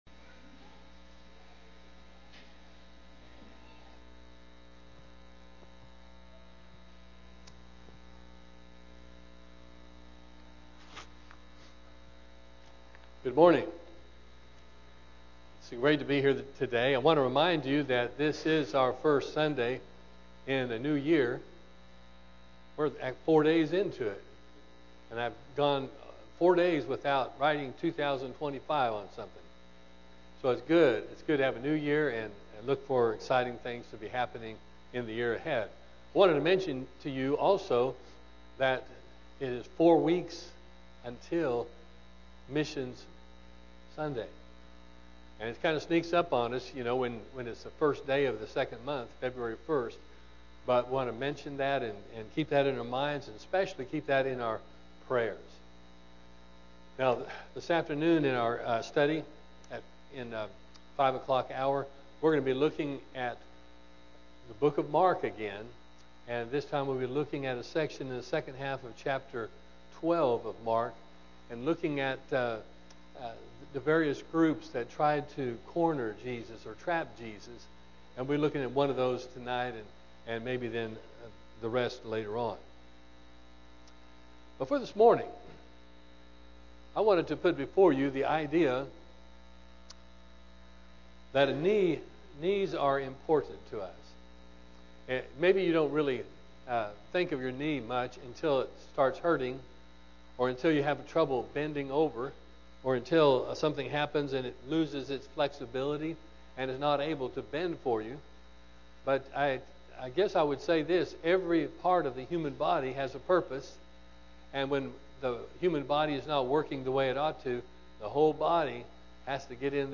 AM Sermon – Greenbrier church of Christ